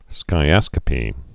(skī-ăskə-pē)